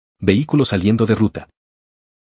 Ademas de las ventanas emergentes se recomienda agregar una alerta de sonido para identificar tanto visualmente de manera sonora y dar seguimiento mas oportuno a escenarios criticos.